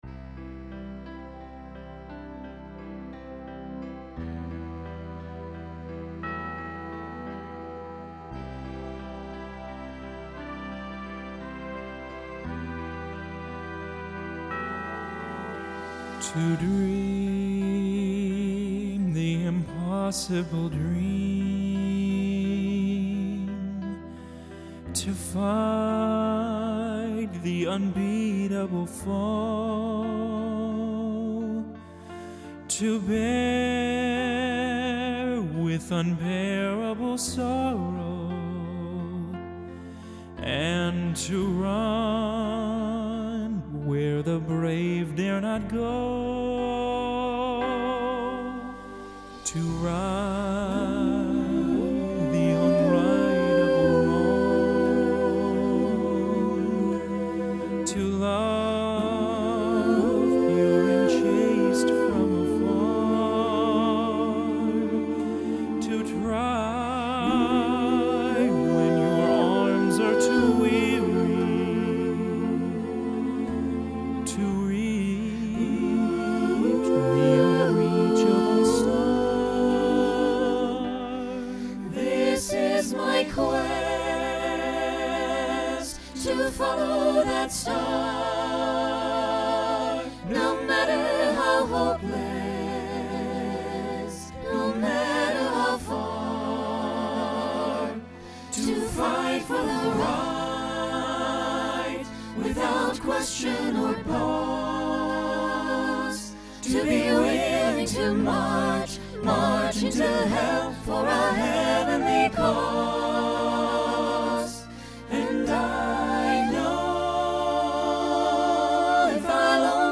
Genre Broadway/Film
Function Ballad Voicing SATB